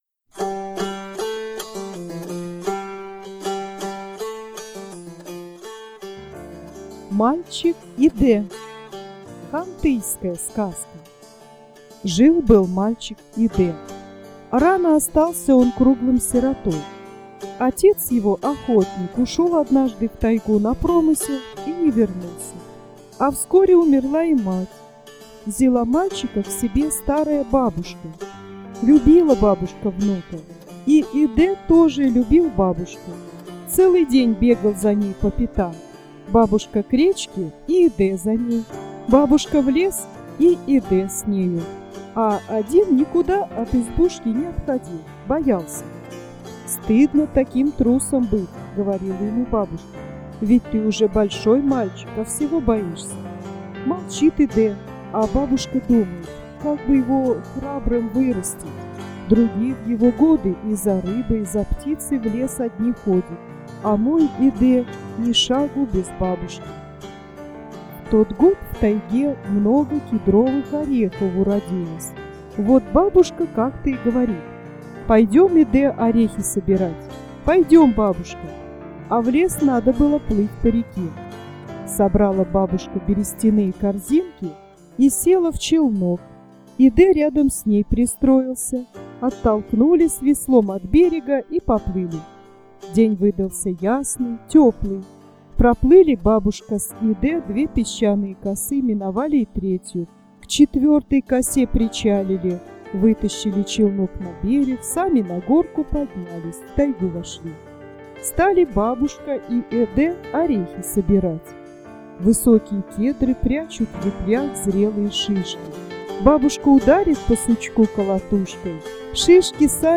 "Мальчик Идэ" - хантыйская народная бытовая аудио сказка о "воспитательном мероприятии", которое бабушка устроила своему внуку, чтобы он стал смелым.